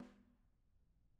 Snare2-HitSN_v1_rr2_Sum.wav